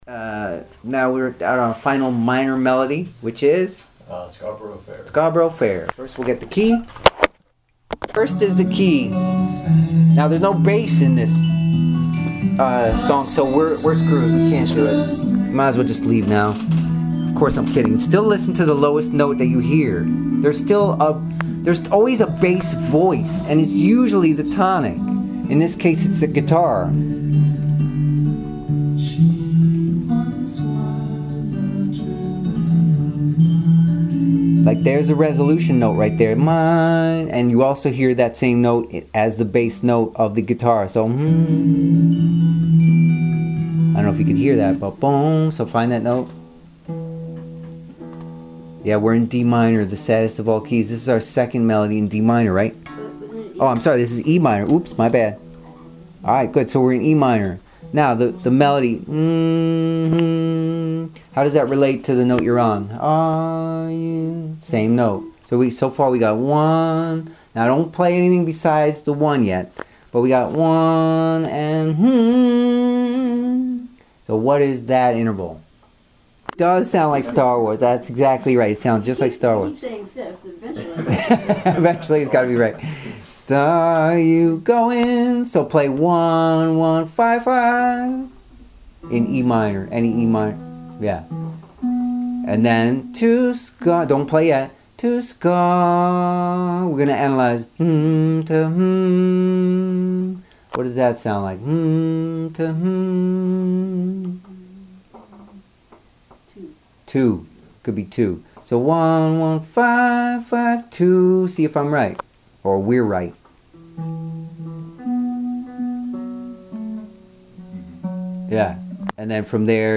Commuter Audio: Ear Training, part 14, famous minor and major melodies - Guitar Lessons in Myrtle Beach, SC
ear-training-famous-major-and-minor-melodies-4.mp3